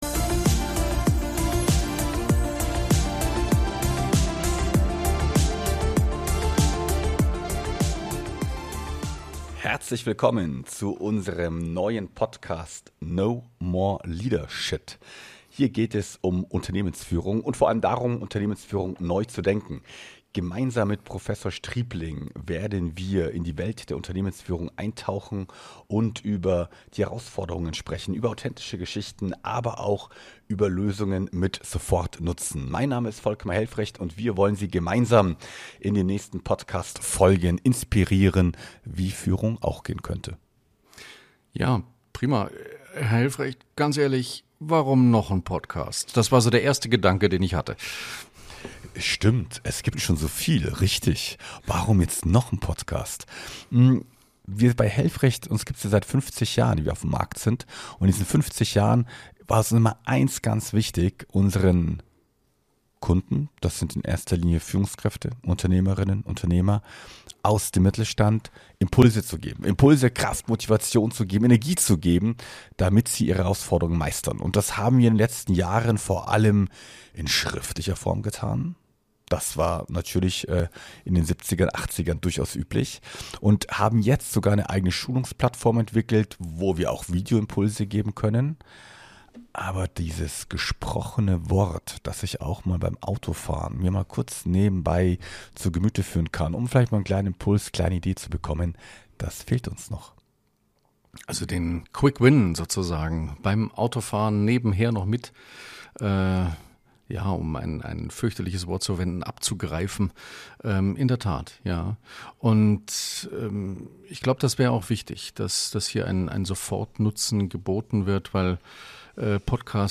vor und geben Ihnen einen Einblick in ihre Hintergründe: Ein